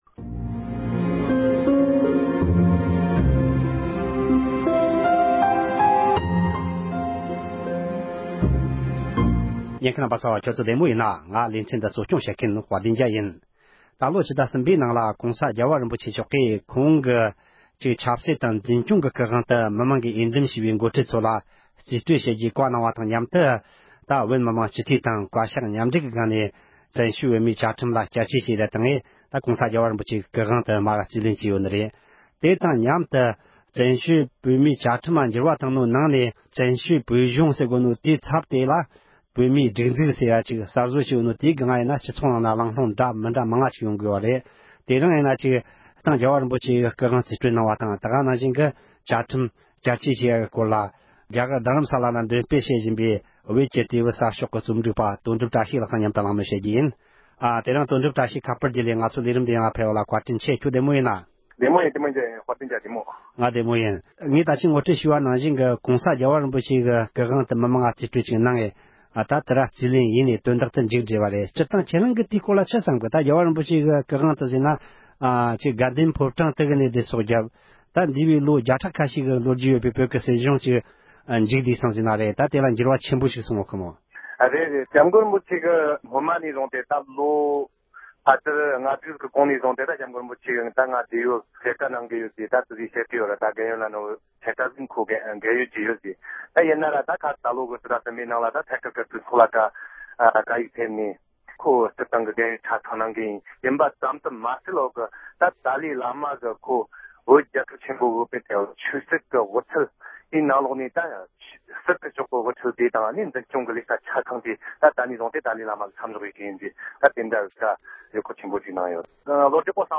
གླེང་མོལ་བྱས་པར་ཉན་རོགས་གནོངས།